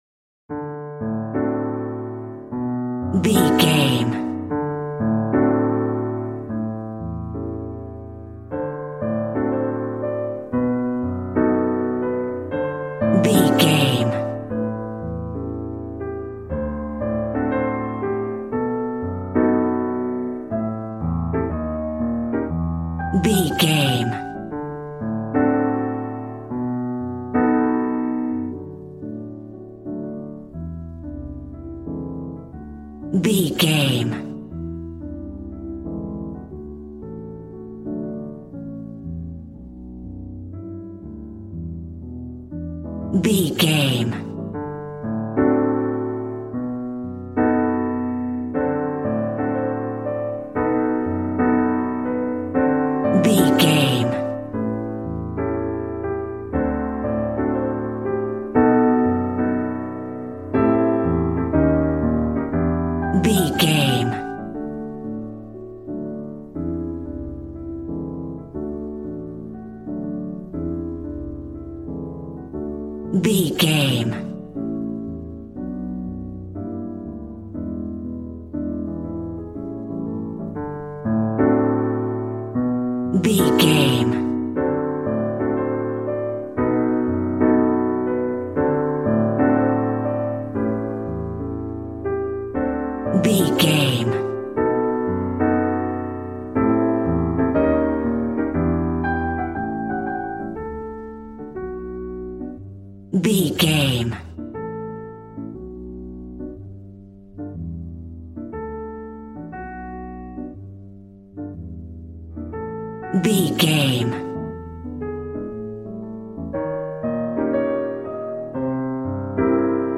Smooth jazz piano mixed with jazz bass and cool jazz drums.,
Ionian/Major
piano
drums